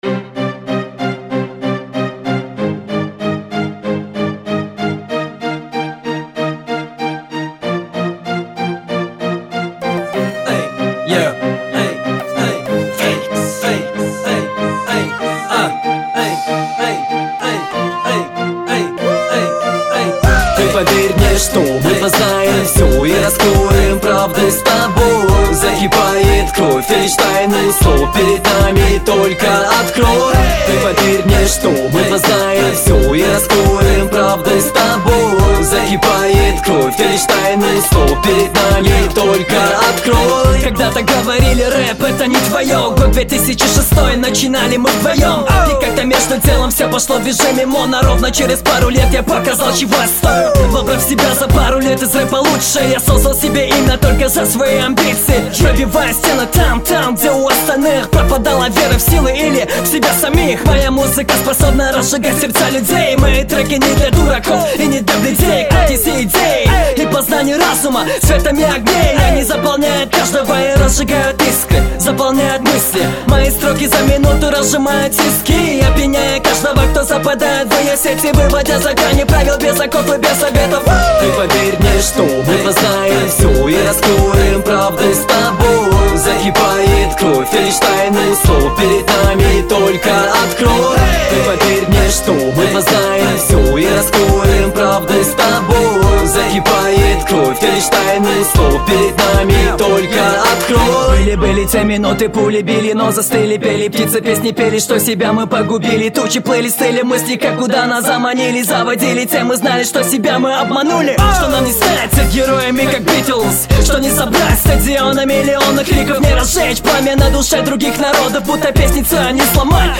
Battle Rap